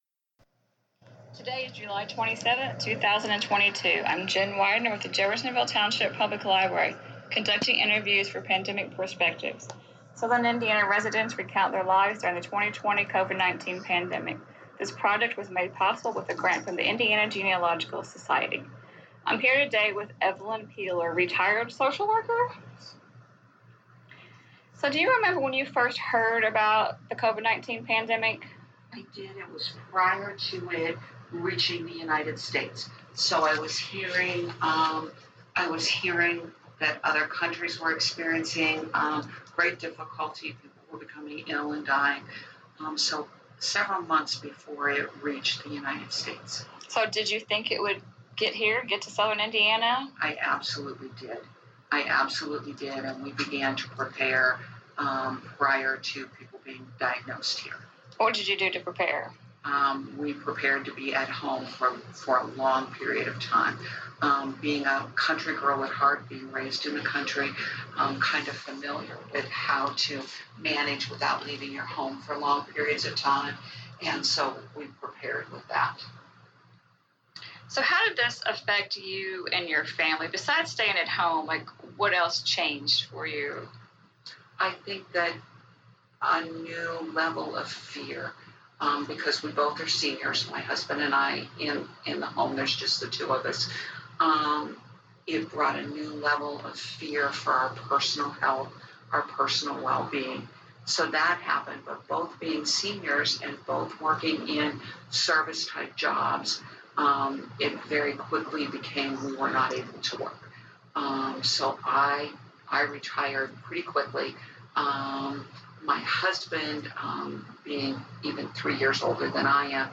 Oral Histories As the Covid-19 pandemic progressed and continued the need to capture people's stories grew.